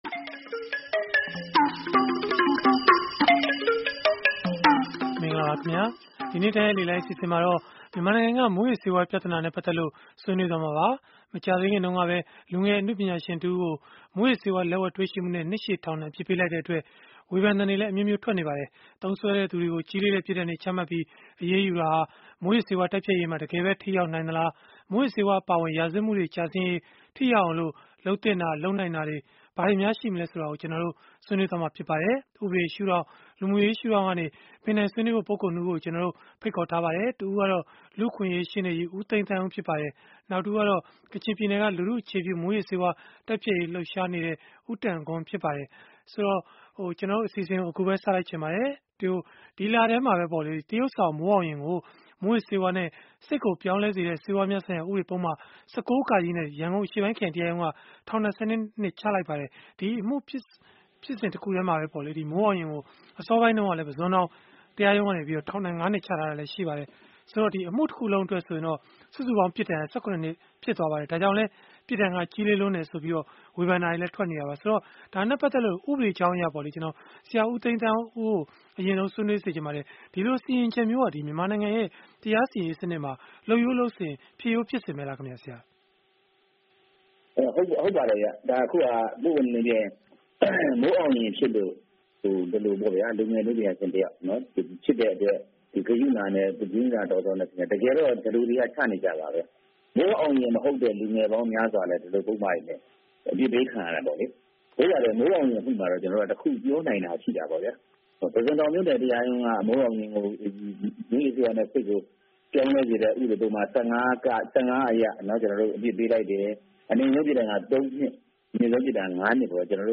ဗွီအိုအေရဲ့ စနေနေ့ည တိုက်ရိုက်လေလှိုင်း အစီအစဉ်မှာ